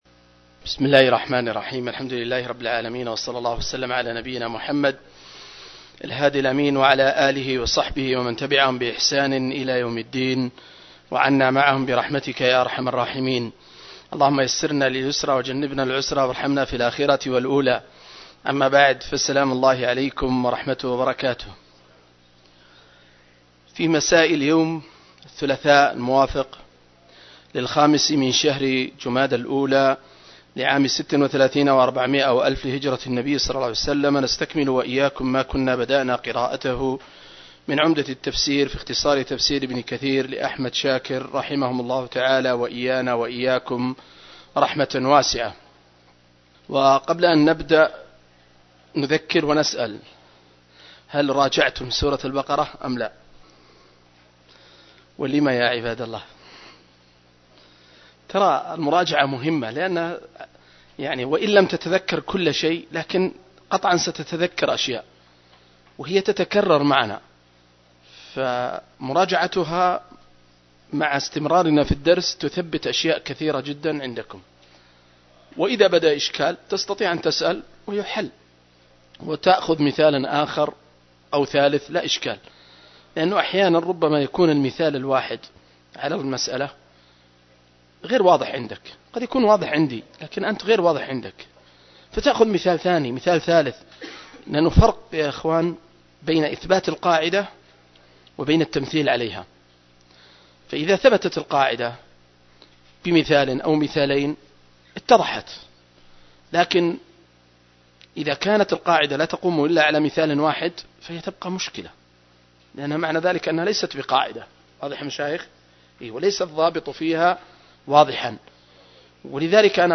068- عمدة التفسير عن الحافظ ابن كثير رحمه الله للعلامة أحمد شاكر رحمه الله – قراءة وتعليق –